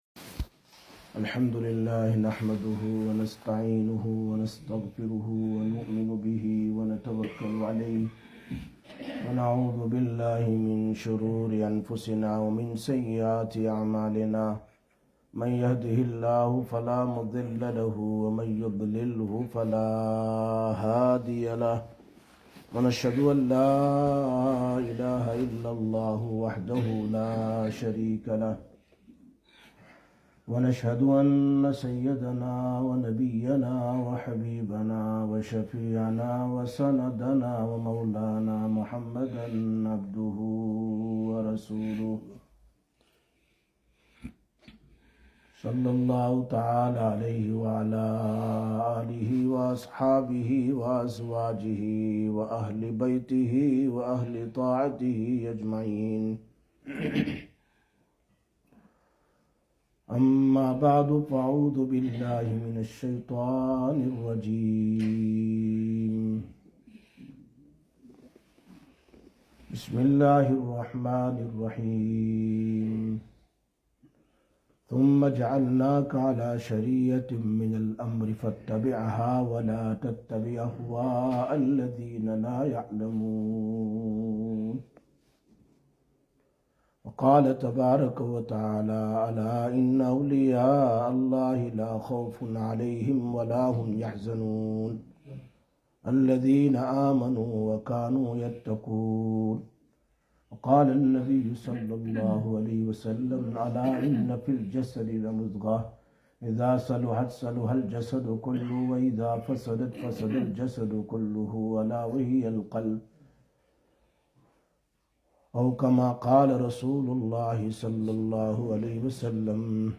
09/06/2023 Jumma Bayan, Masjid Quba